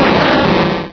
pokeemerald / sound / direct_sound_samples / cries / forretress.aif
forretress.aif